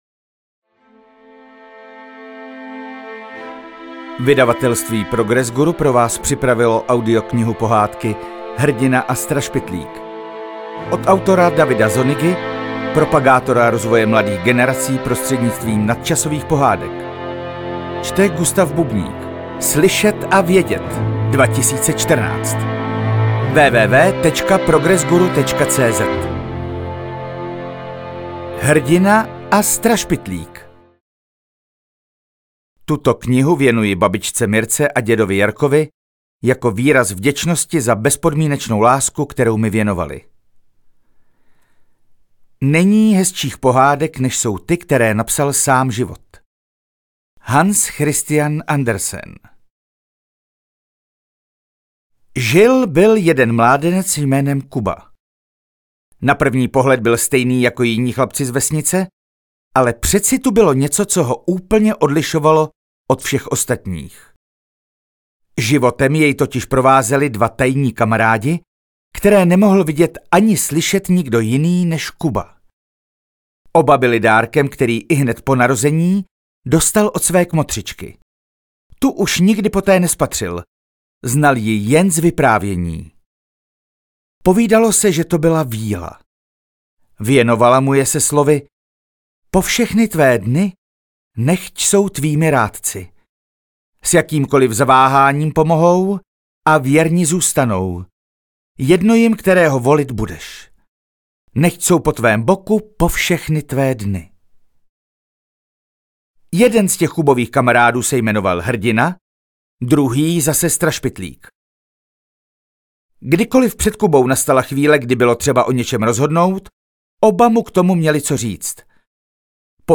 AudioKniha ke stažení, 6 x mp3, délka 51 min., velikost 70,2 MB, česky